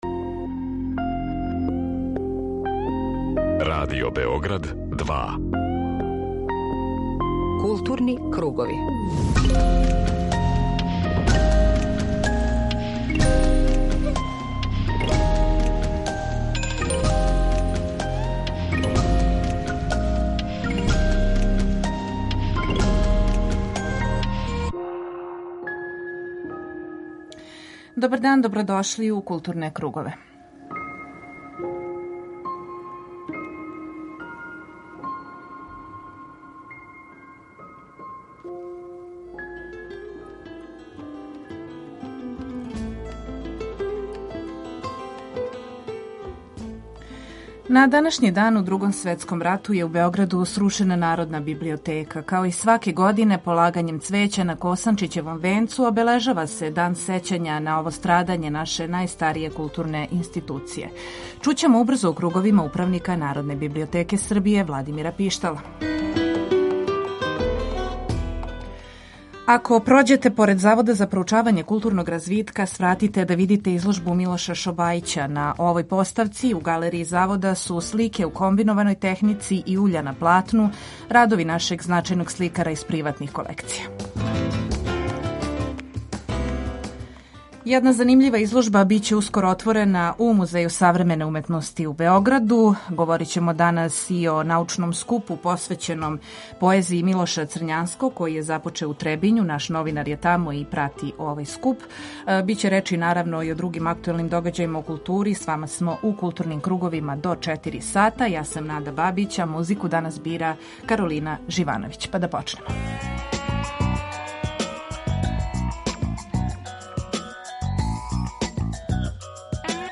На Косанчићевом венцу у Београду обележен је Дан сећања на страдање Народне библиотеке Србије у Другом светском рату. Чућемо Владимира Пиштала, управника Народне библиотеке Србије.